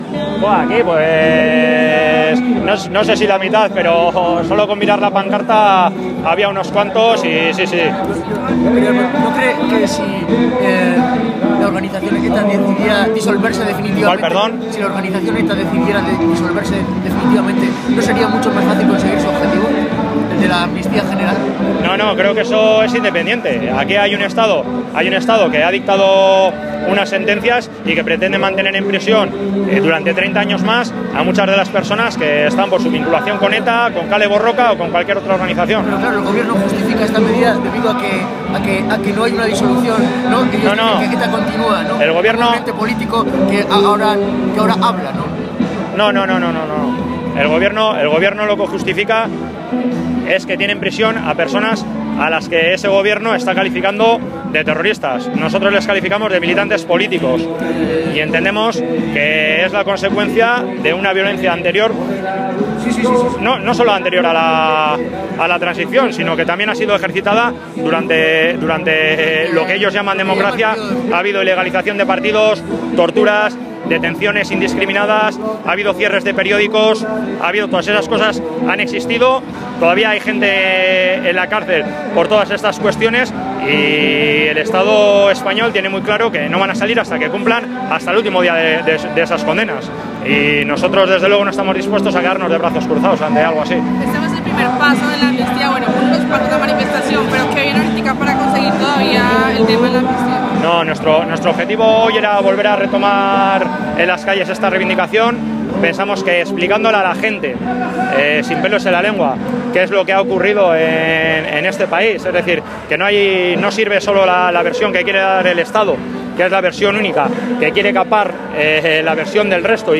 al término de la marcha